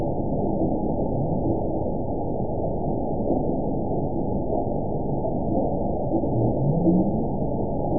event 922662 date 02/27/25 time 04:28:46 GMT (3 months, 2 weeks ago) score 9.16 location TSS-AB02 detected by nrw target species NRW annotations +NRW Spectrogram: Frequency (kHz) vs. Time (s) audio not available .wav